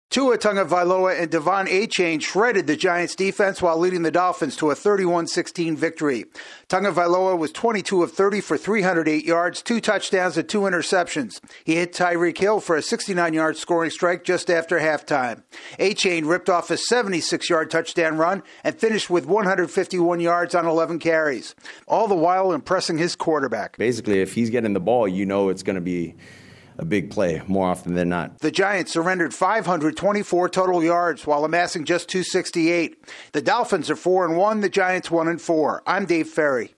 The Dolphins rebound from their first loss of the season. AP correspondent